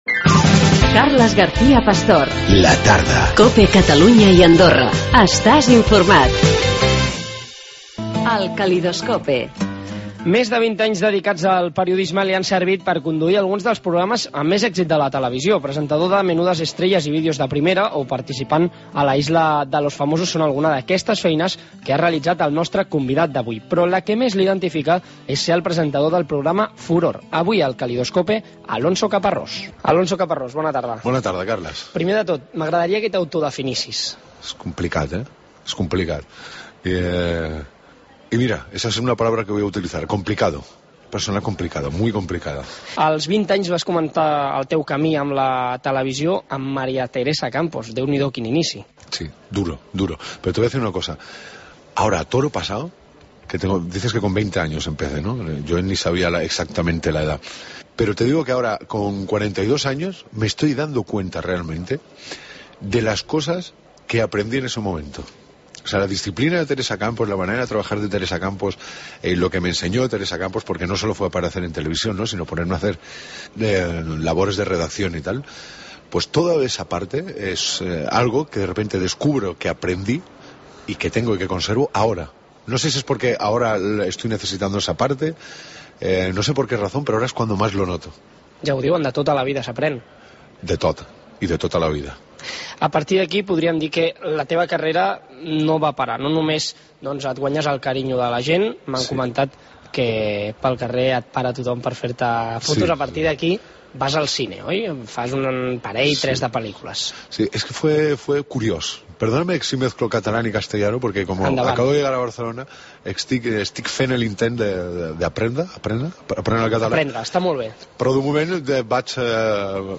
Alonso Caparrós, presentador de televisió